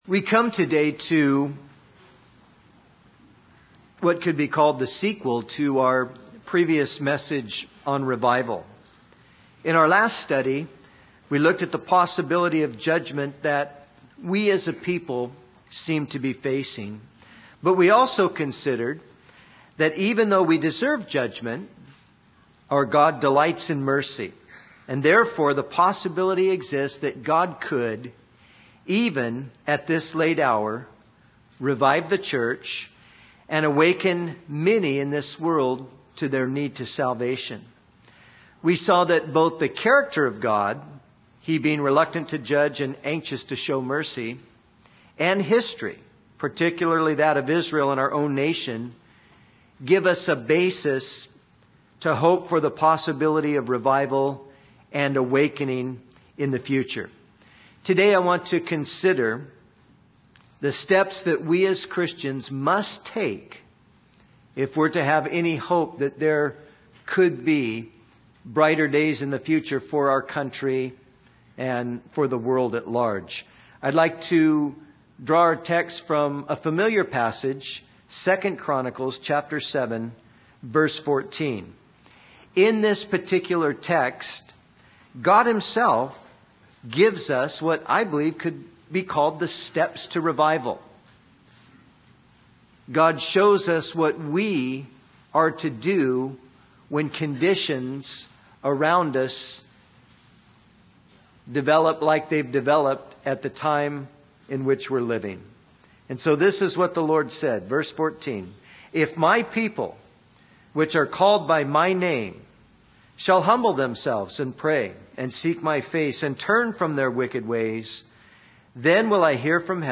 In this sermon, the preacher emphasizes that the message of revival is directed specifically to the church of God. The four steps to revival, according to the passage in 2 Chronicles 7:14, are acknowledgement of sin, repentance from sin, complete devotion, and prayer.